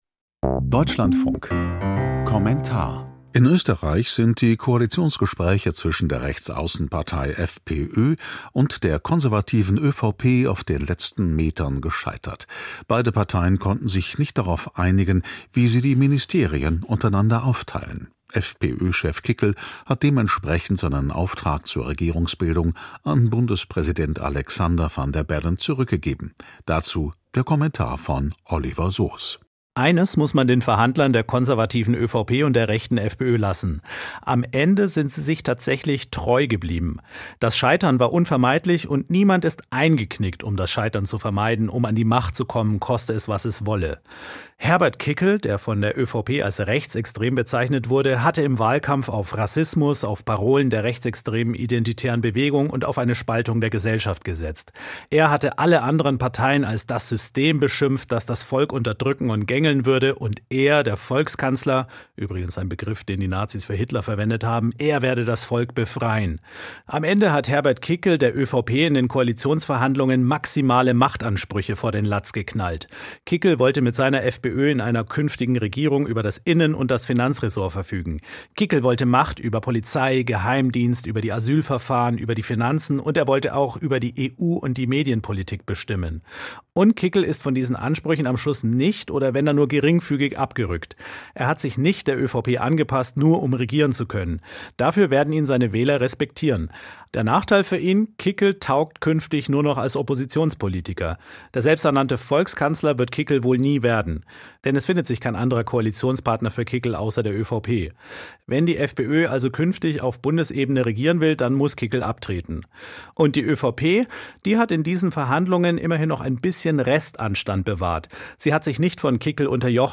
Kommentar zu Koalitionsverhandlungen in Österreich: Scheitern war unvermeidlich